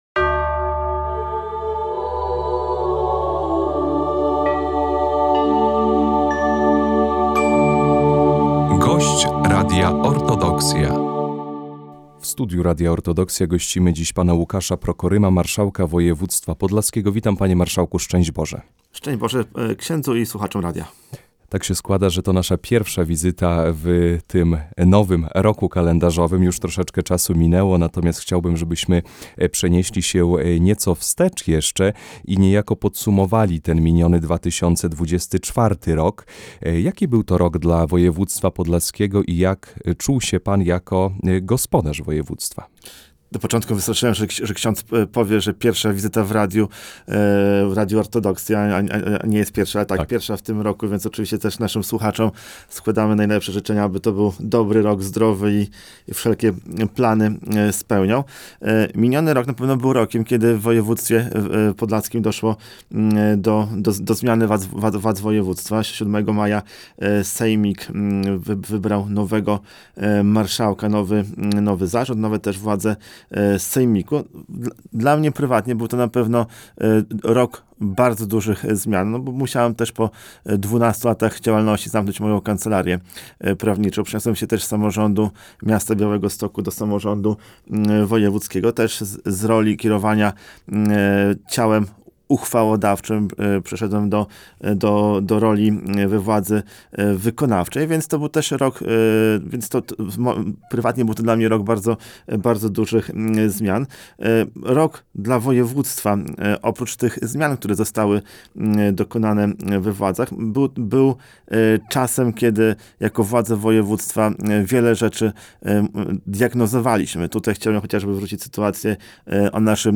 Rozmowa z marszałkiem Województwa Podlaskiego Łukaszem Prokorymem
Zapraszamy do wysłuchania rozmowy z marszałkiem Województwa Podlaskiego panem Łukaszem Prokorymem.